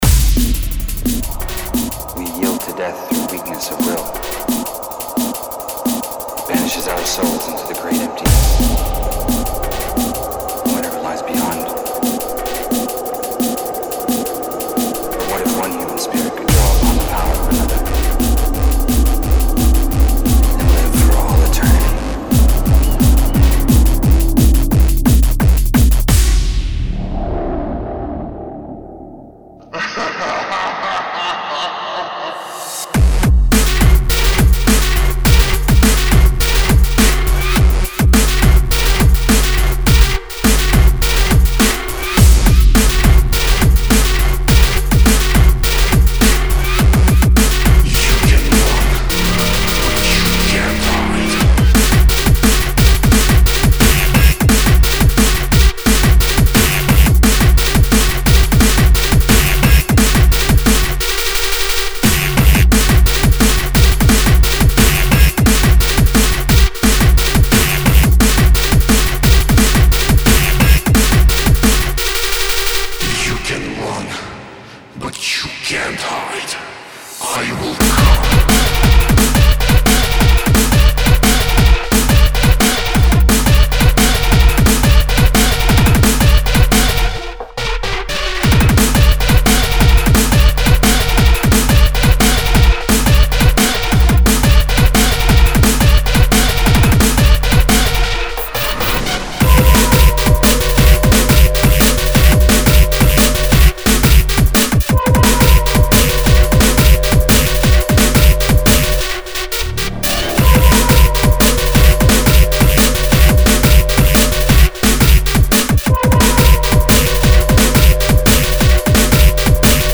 Genres Drum & Bass